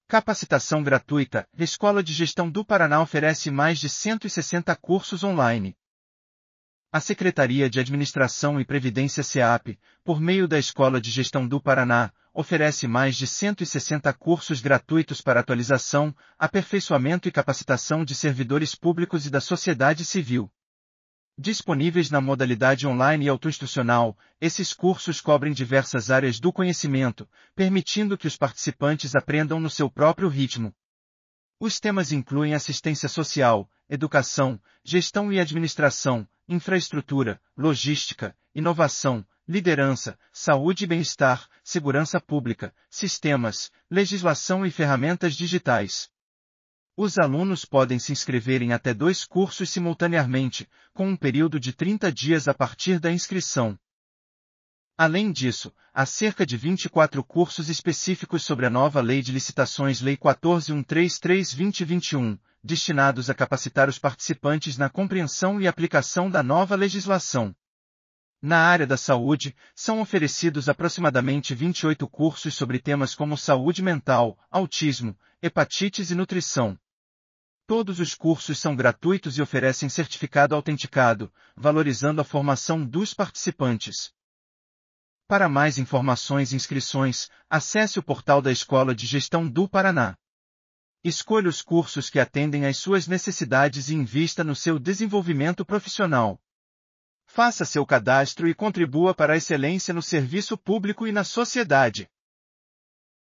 audionoticia_cursos_egp.mp3